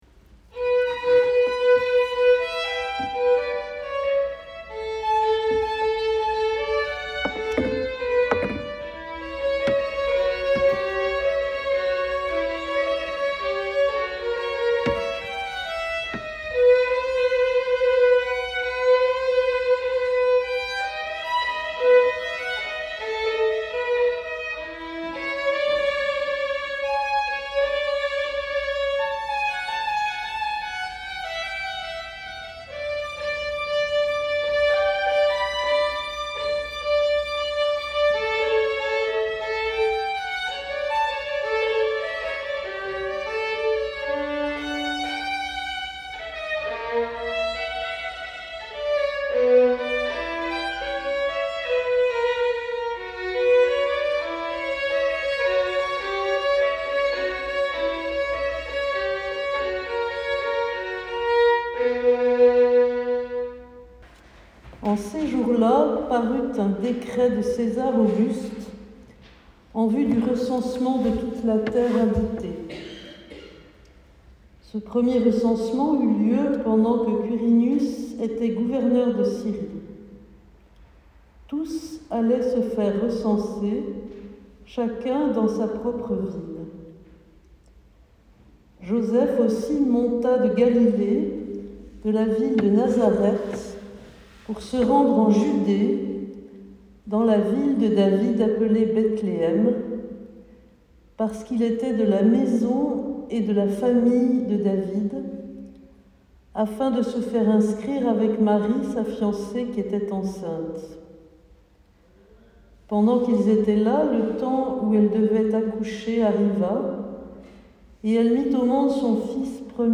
pièces de violon
lectures et prédication.